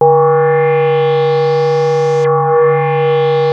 JUP 8 E5 9.wav